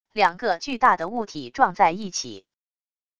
两个巨大的物体撞在一起wav音频